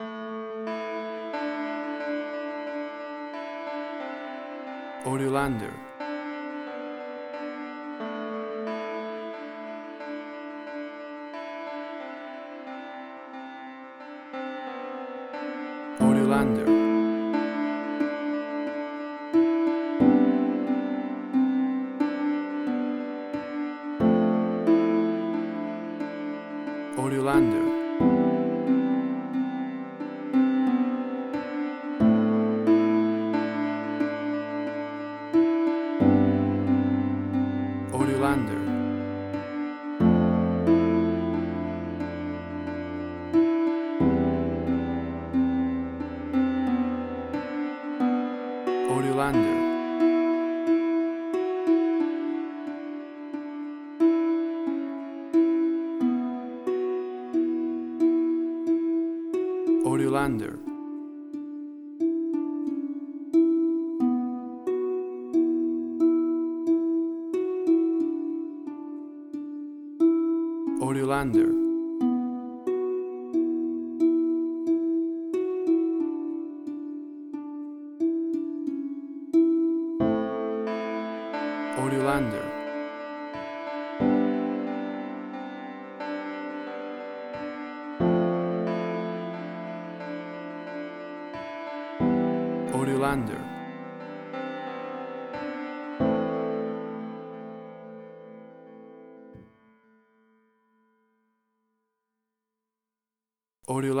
Suspense, Drama, Quirky, Emotional.
Tempo (BPM): 90